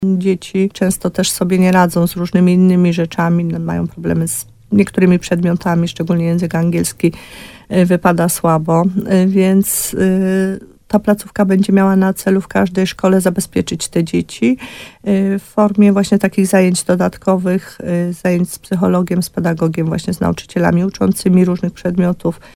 – Będzie udzielana pomoc psychologiczna, ale planowane są też zajęcia wyrównujące z różnych przedmiotów szkolnych – mówi wójt Małgorzata Gromala.